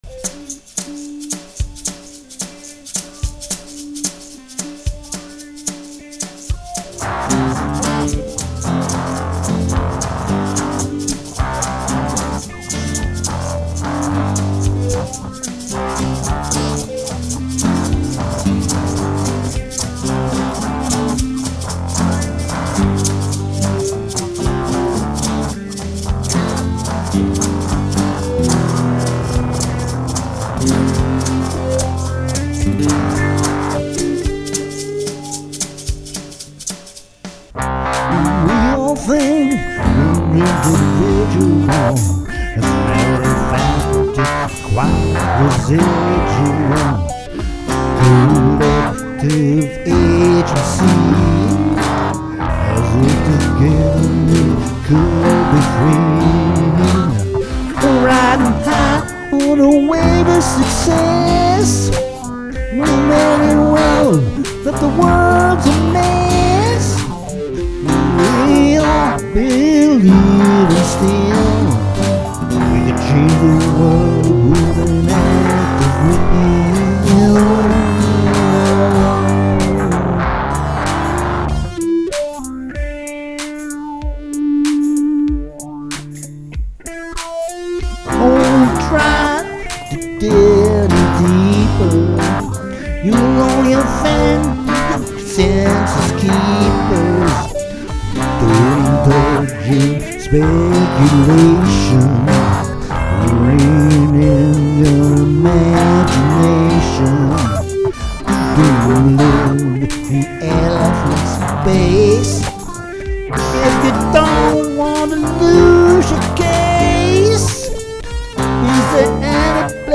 Philorock in D and A minor
Chet Atkins Gretsch on Slash fuzz bass
Takamine acoustic with Vox reverb